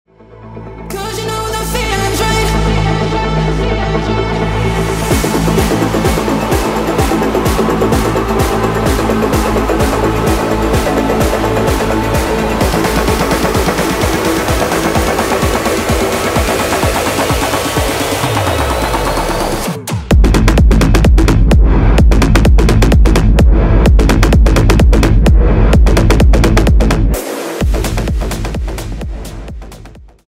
Genre : Blues.